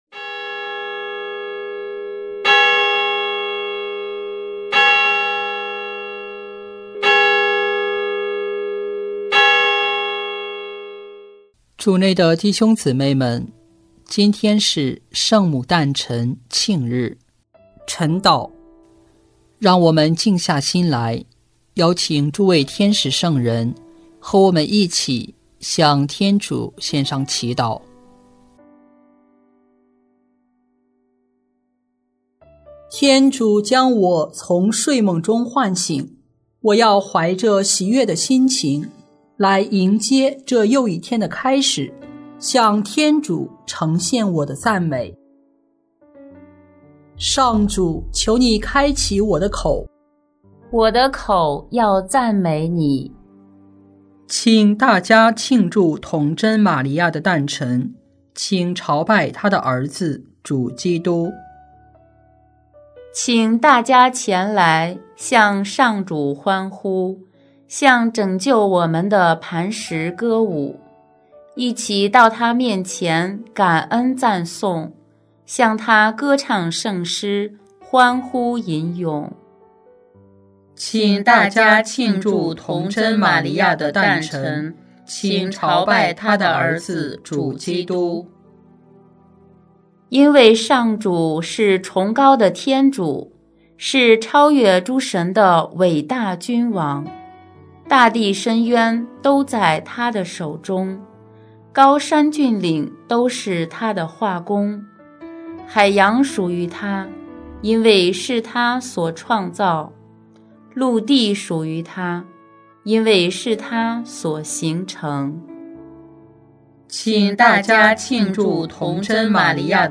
圣咏吟唱